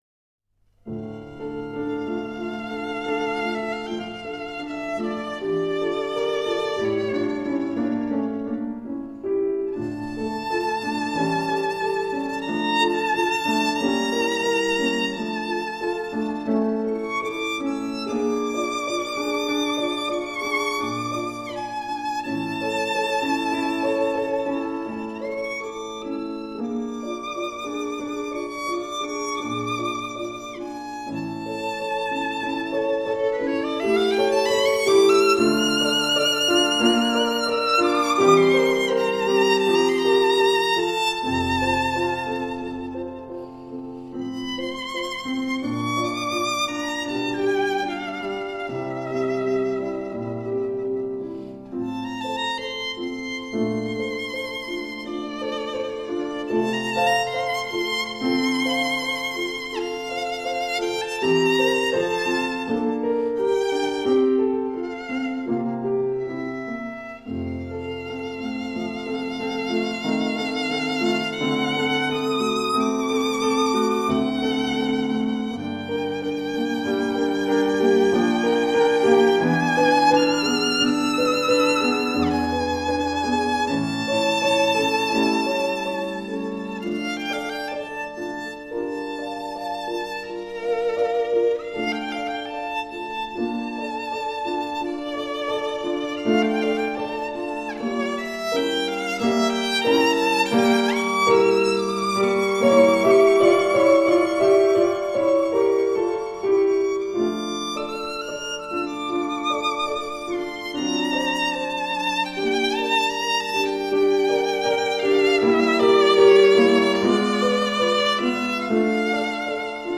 旋律轻缓柔美 音色悠闲浪漫
西洋古典音乐及小品